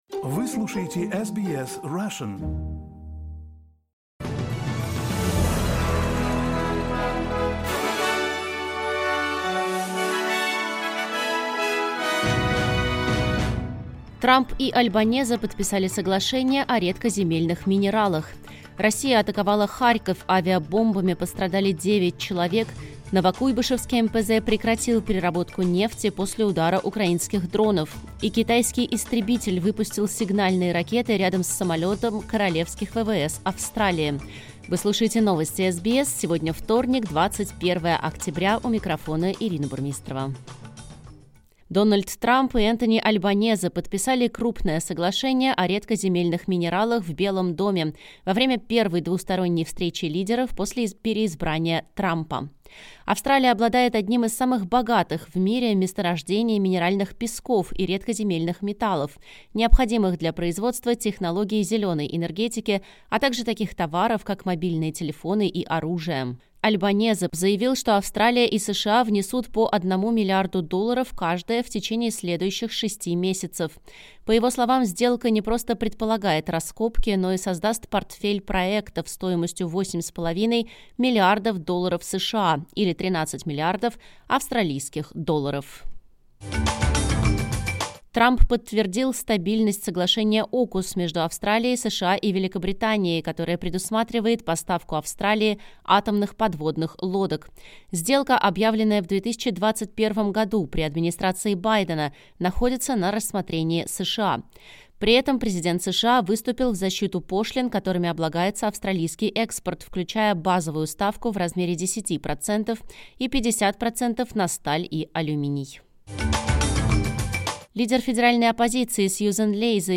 Новости SBS на русском языке — 21.10.2025